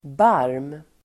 Ladda ner uttalet
Uttal: [bar:m]